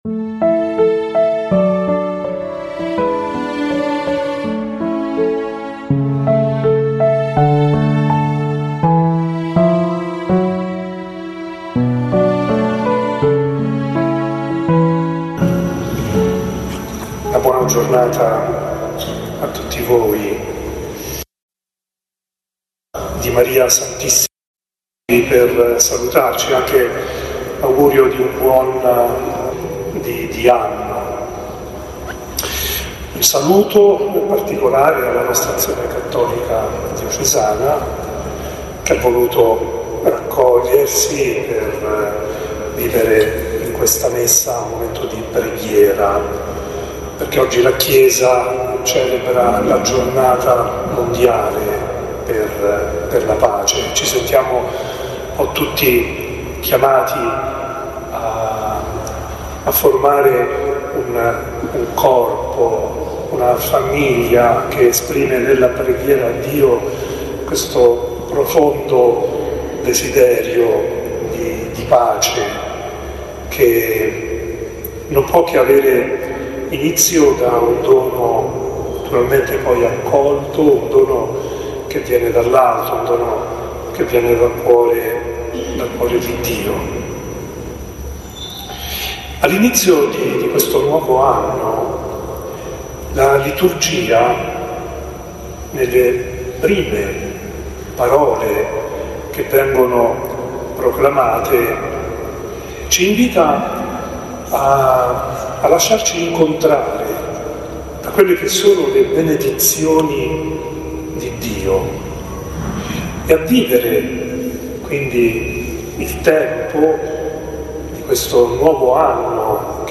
CATTEDRALE DI TRANI OMELIA DELL’ARCIVESCOVO NELLA SOLLENITA’ DELLA MADRE DI DIO CON AC DIOCESANA
Arcivescovo Mons. Leonardo D’Ascenzo
CATTEDRALE-DI-TRANI-OMELIA-DELLARCIVESCOVO-NELLA-SOLLENITA-DELLA-MADRE-DI-DIO-CON-AC-DIOCESANA-.mp3